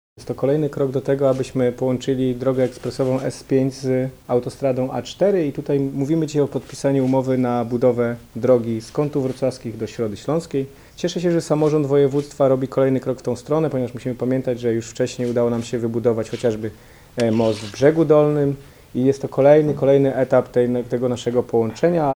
Modernizacja drogi nr 346 to kolejny krok w kierunku połączenia drogi ekspresowej S5 z autostradą A4 w regionie – dodaje Michał Rado, wicemarszałek województwa dolnośląskiego.